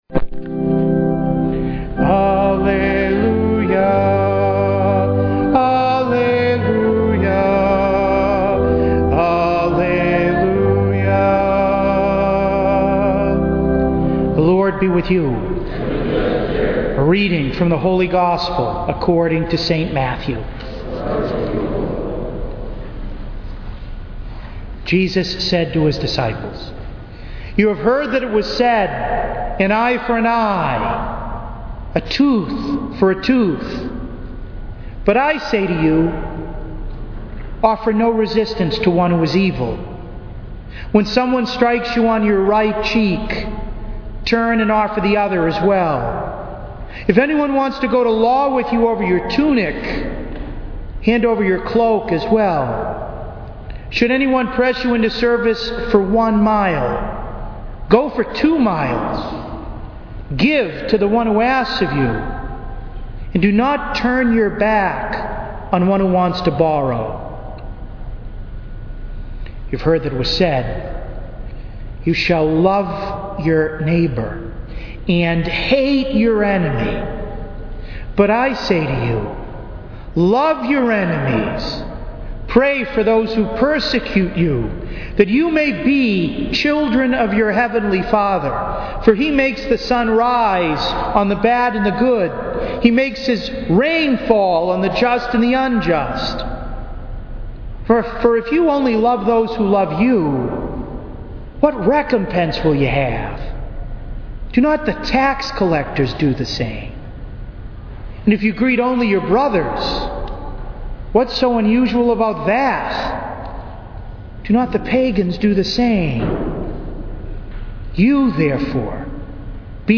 To listen to an audio recording of today’s homily, please click below: